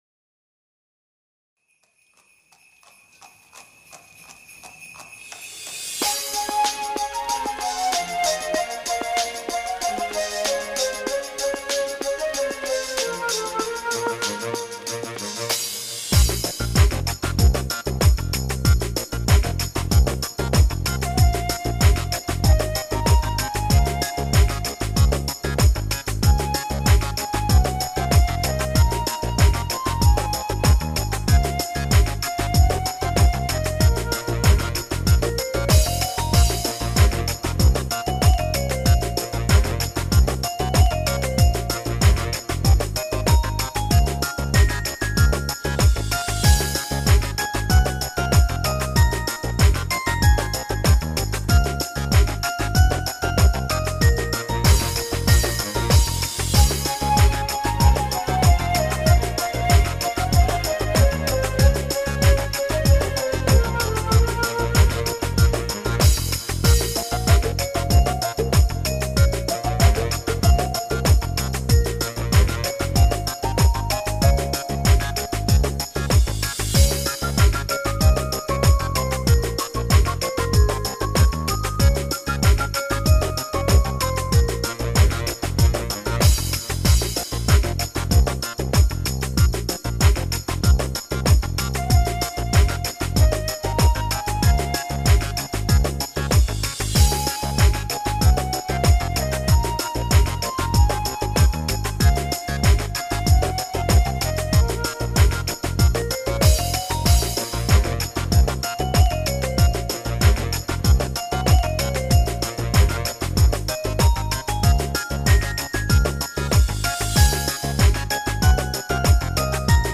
минусовка версия 219117